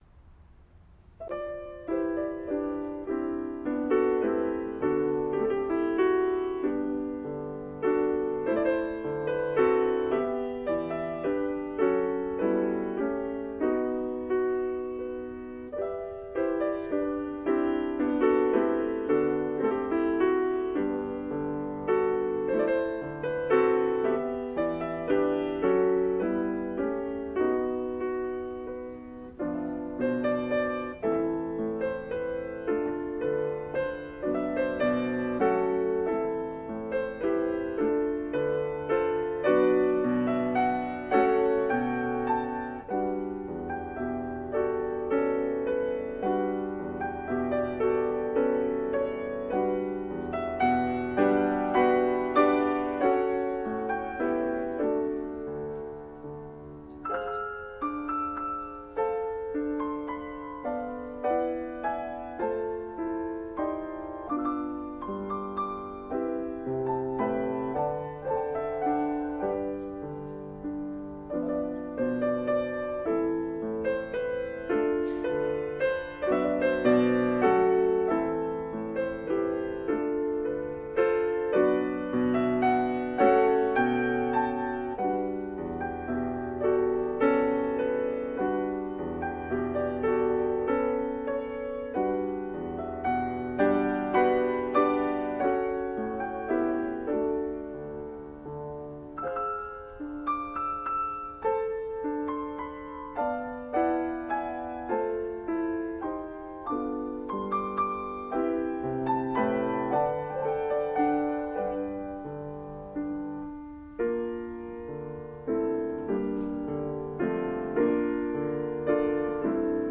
G 장조 알레그로 모데라토, 3/4박자이며, 세도막 형식으로 구성되어 있다.[5] 이 곡은 라벨이 제대 후 요양했던 집의 주인인 장 드뢰퓌스에게 헌정되었다.